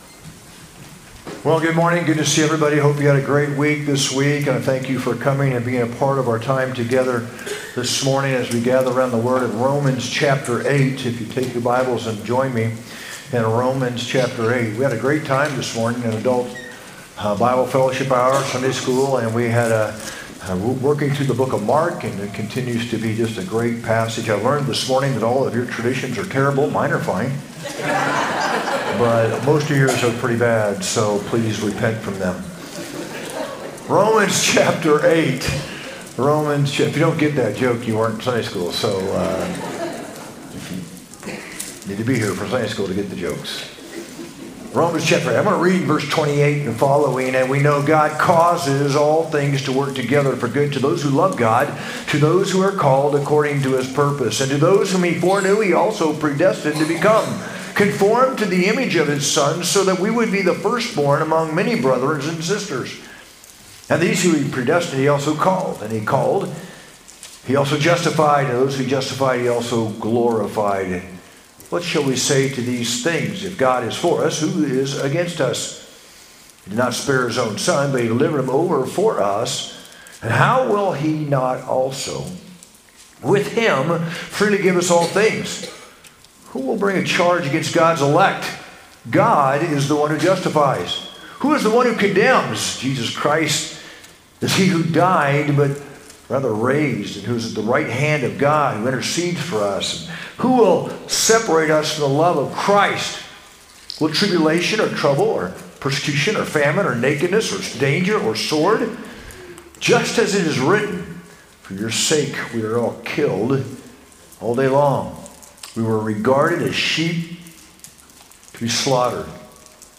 sermon-2-2-25.mp3